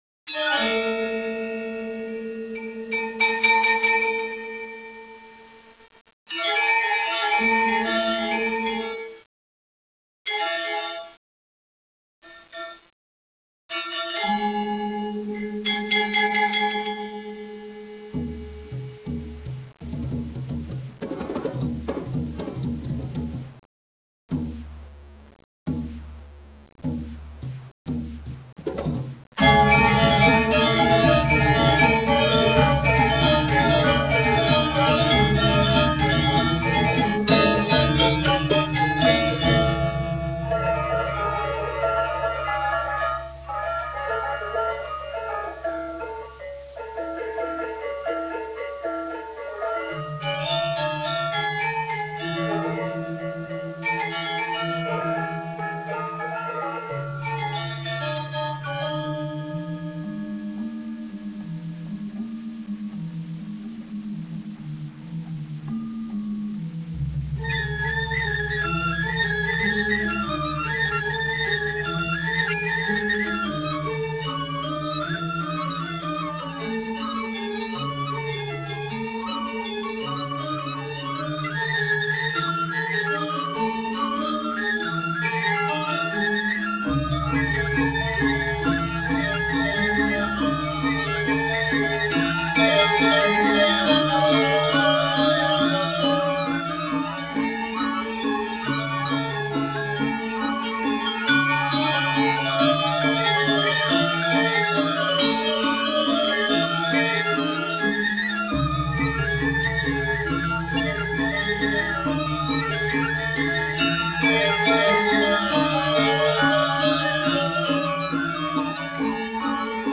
In the evening, we went to see the Gabor dance in a temple setting with a rising full moon in the background. The gamelan musicians were amazing.
LISTEN TO A GAMELAN BAND
gamelan2.aif